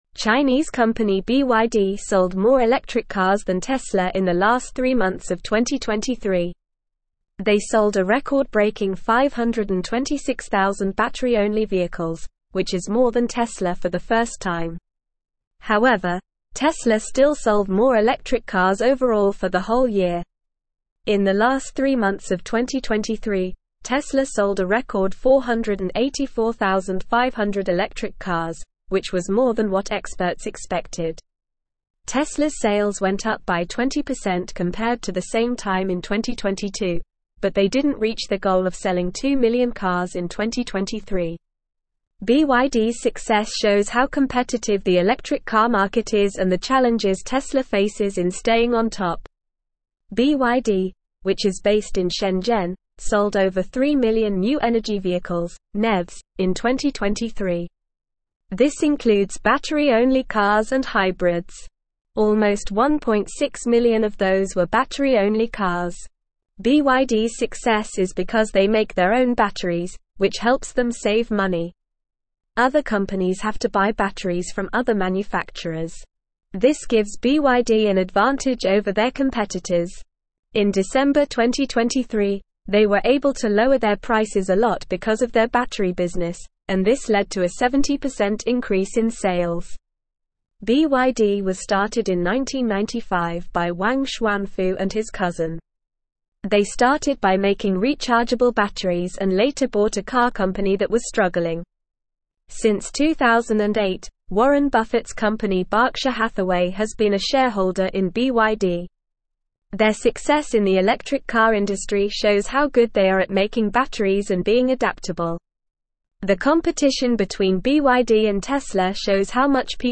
Normal
English-Newsroom-Upper-Intermediate-NORMAL-Reading-BYD-Overtakes-Tesla-in-Electric-Vehicle-Sales.mp3